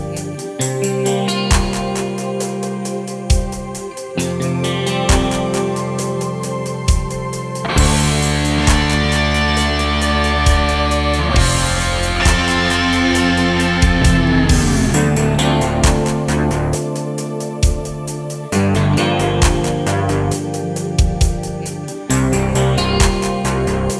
no Backing Vocals Rock 5:24 Buy £1.50